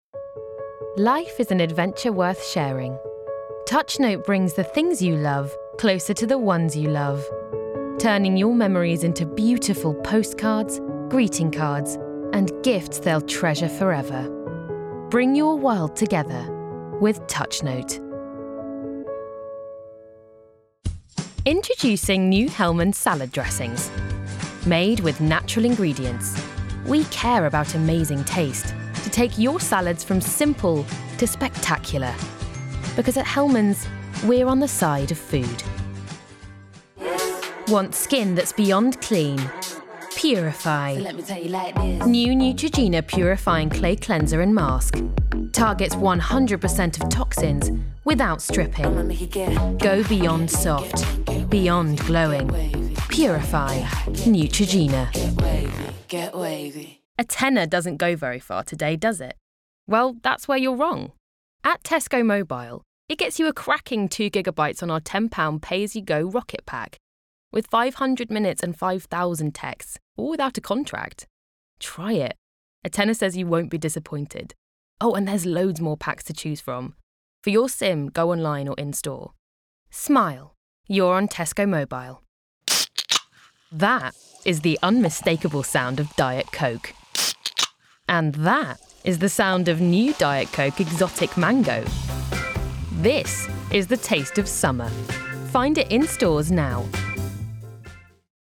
Commercial Reel
• Native Accent: RP
• Home Studio